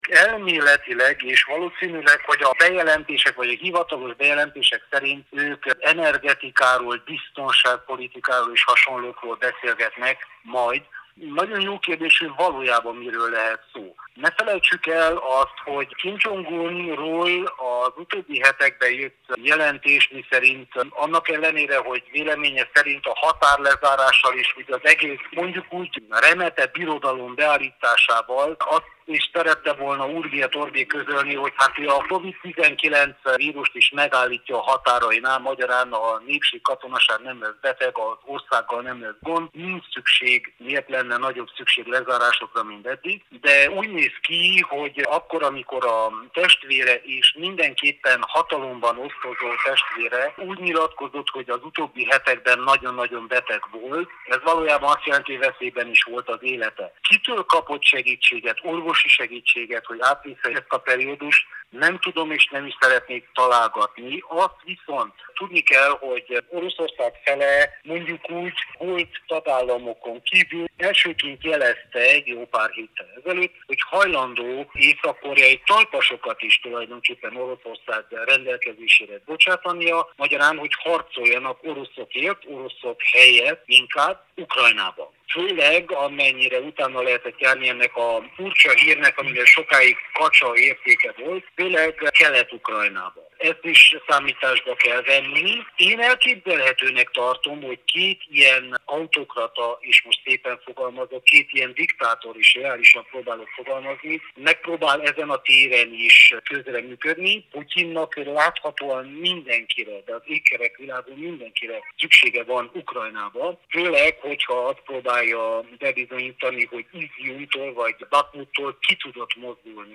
rádiónknak adott nyilatkozatában